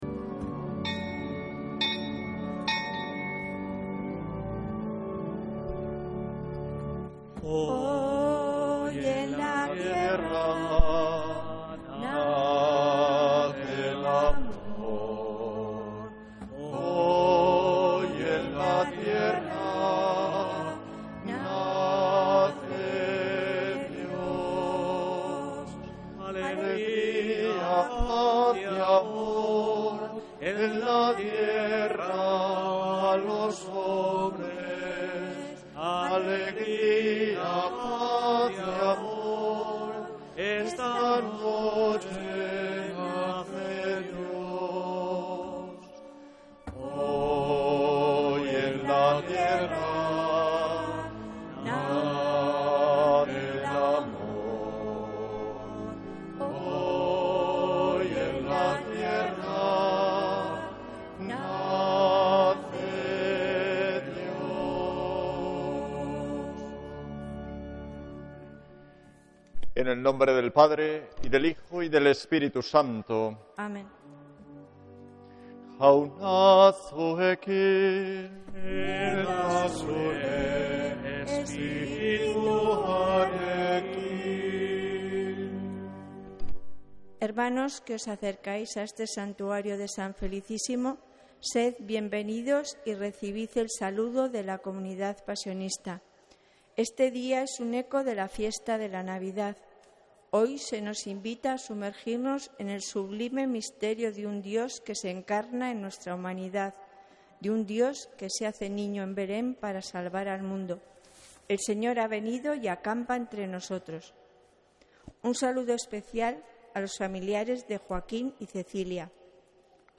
Santa Misa desde San Felicísimo en Deusto, domingo 4 de enero de 2026